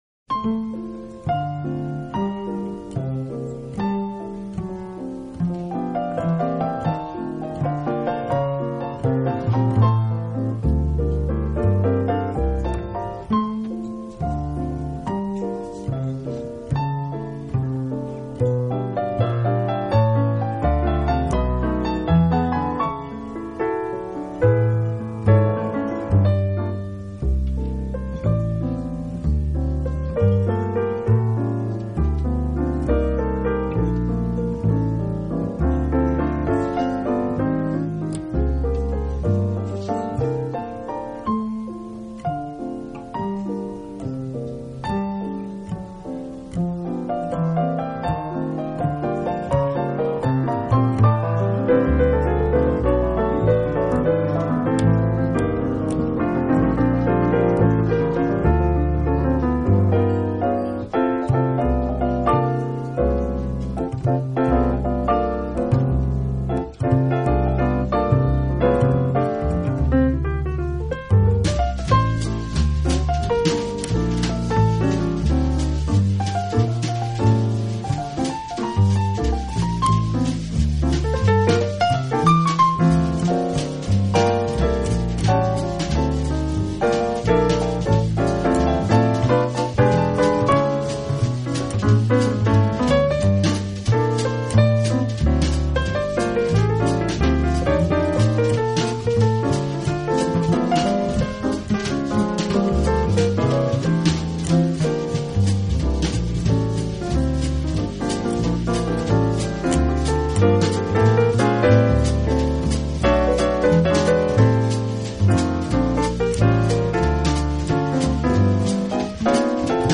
乐器编制采简单的三重奏
piano
bass
drums